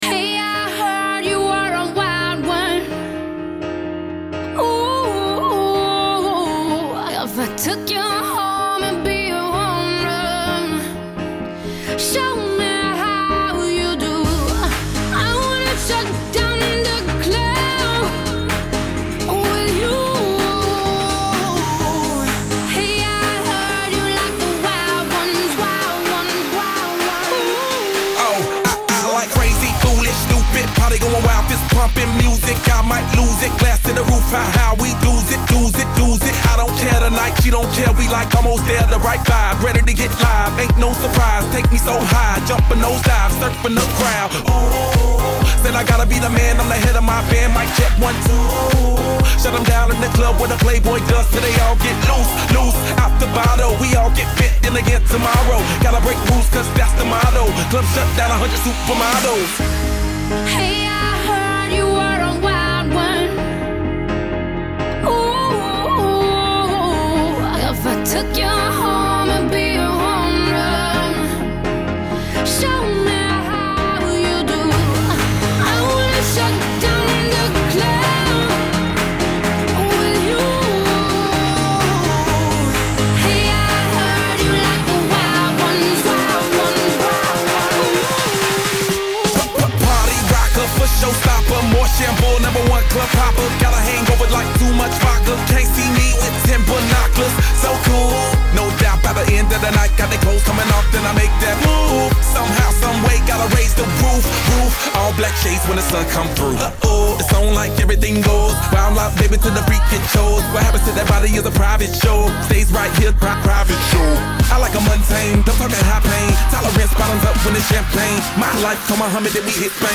HipHop 2010er